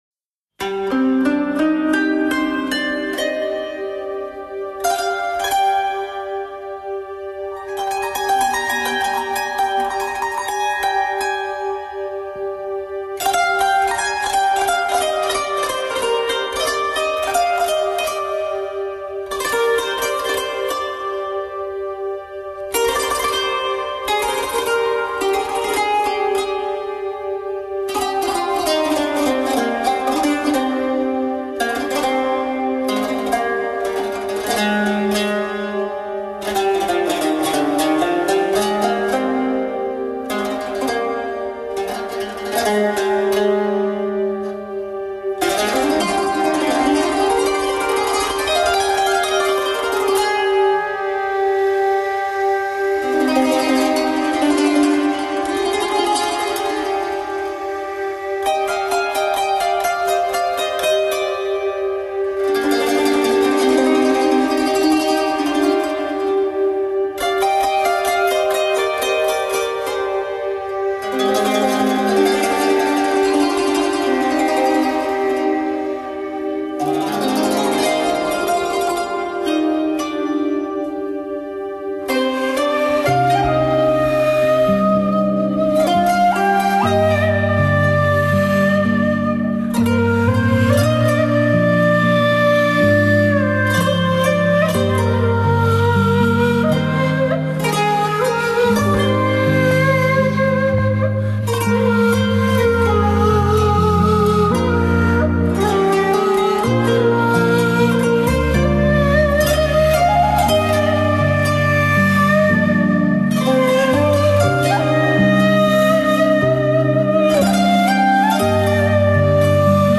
土耳其笛Ney、斑鸠琴、人声…，华丽异国的情调，如香料般活化了听觉世界，